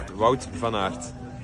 marrant, je viens de voir qu'il y a la prononciation à coté du nom du PCS pour les plus connus